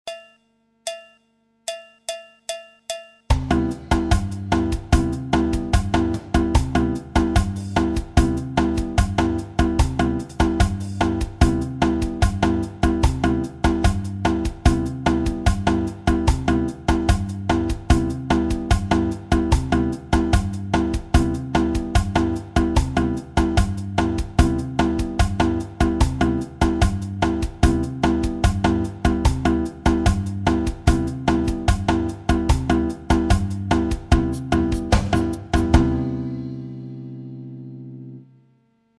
La guitare brésilienne et les rythmes brésiliens
J'ai mis un pattern de batterie avec clave samba et tambourim 1 samba. Pour donner une impression de surdo avec la guitare Il suffit d'alterner les basses, par exemple sur un do 7 majeur les basses sont do et sol, le do sur le premier temps et le sol sur le deuxieme temps comme le surdo.
La samba avec imitation surdo sur les basses